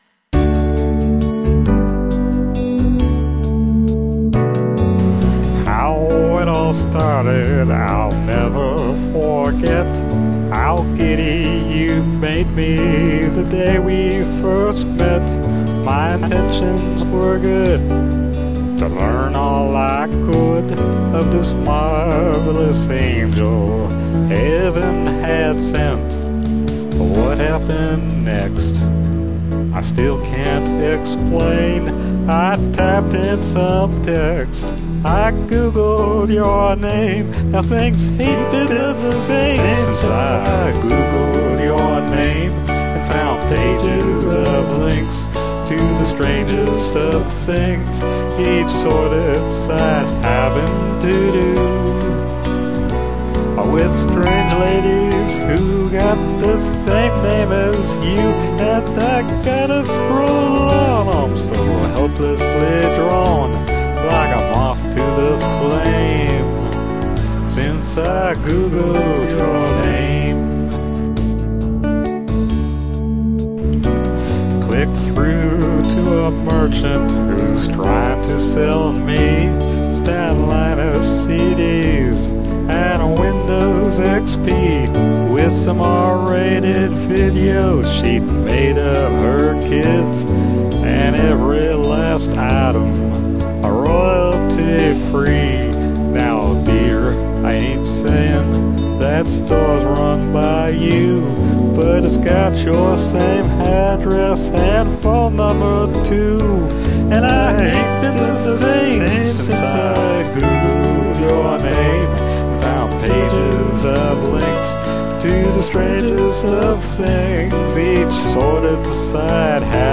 country waltz, male voice